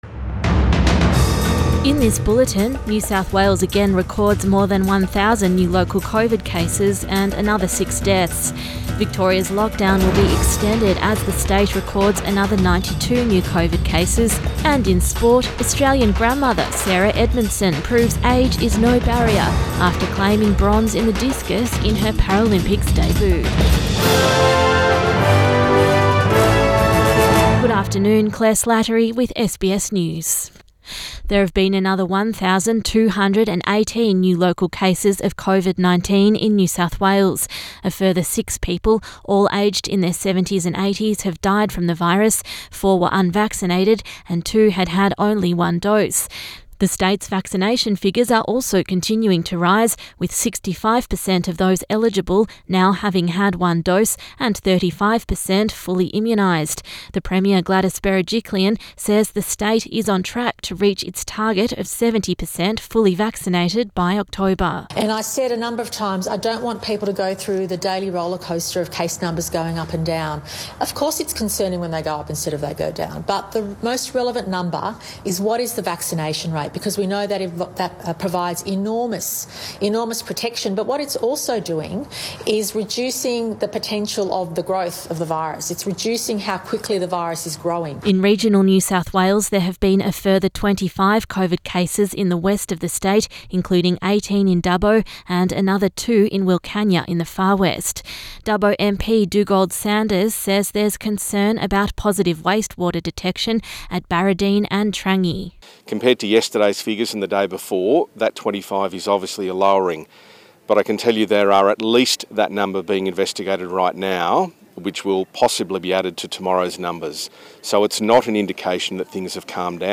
PM bulletin 29 August 2021